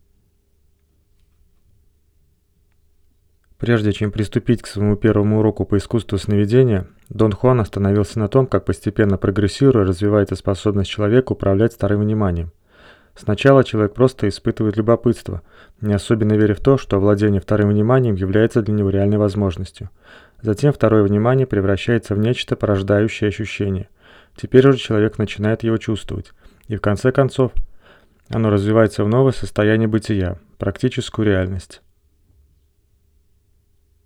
Ленточный микрофон "АПЛ" (Алюминиевая-Поталевая-Лента) лента 0,2 микрона.